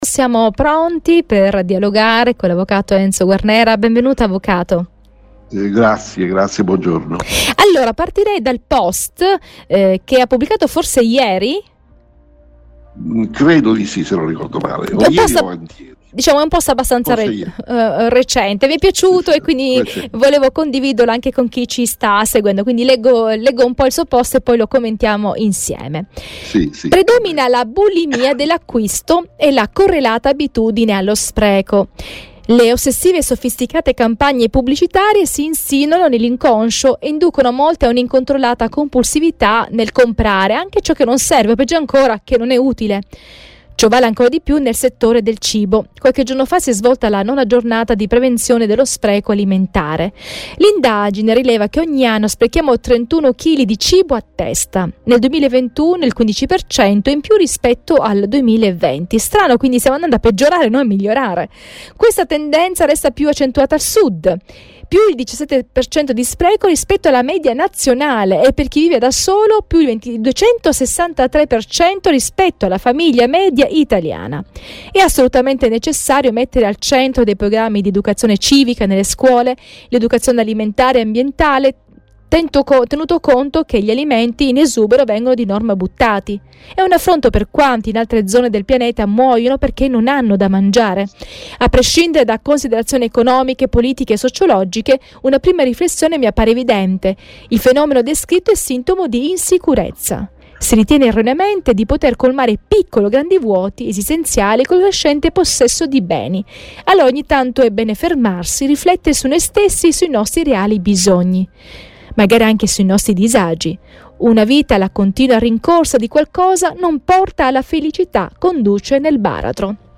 Spreco e bulimia degli acquisti sono stati gli argomenti del dialogo avuto oggi